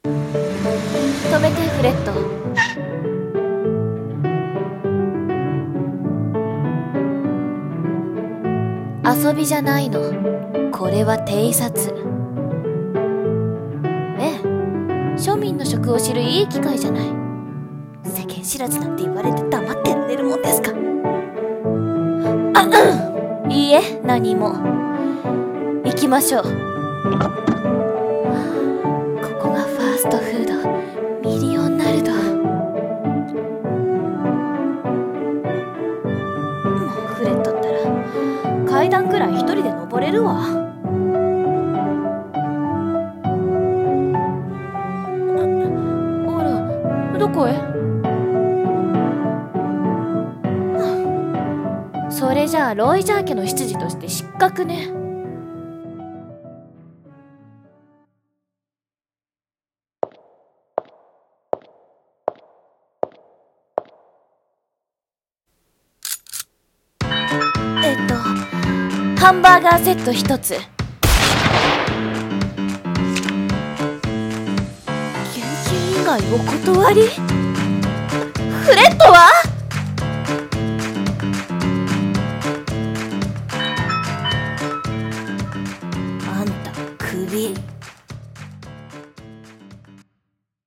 二人声劇【Valet×Bullet】